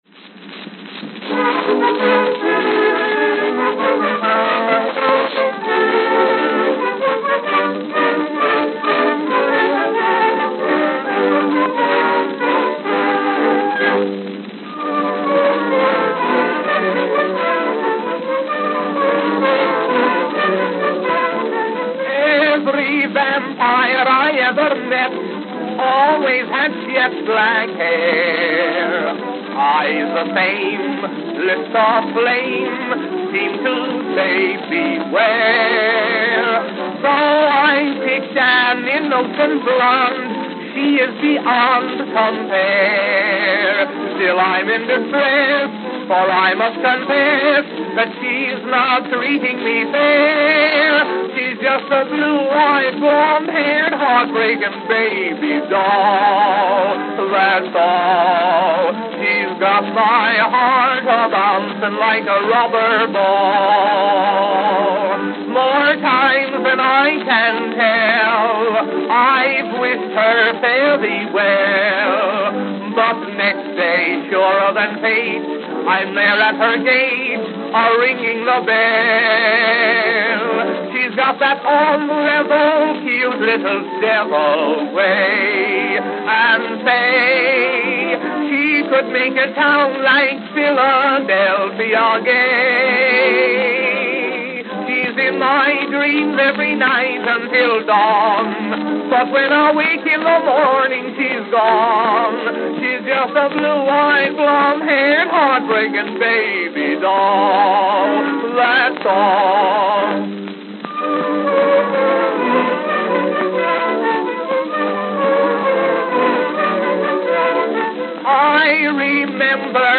Note: Very worn with stripped groove (skips) at start.